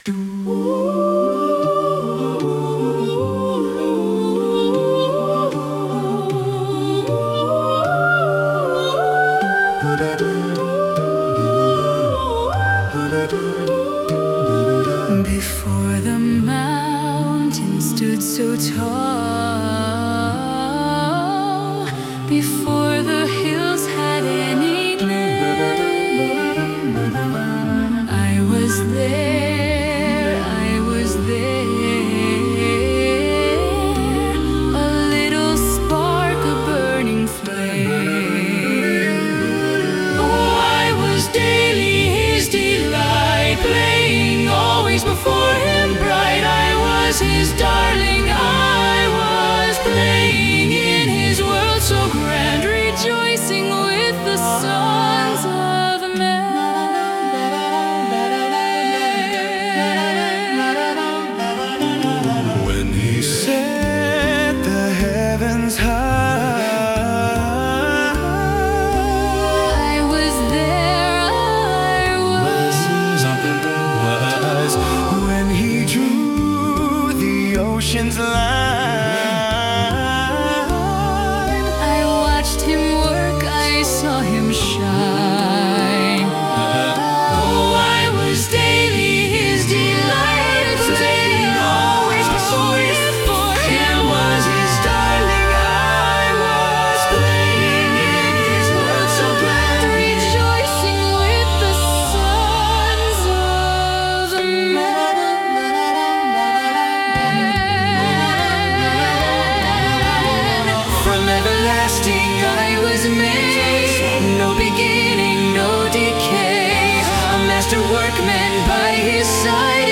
An recent adaptation of the choral fugue of Julia’s complaint in Scene 2. The new lyrics are based on Proverbs 8:22-31.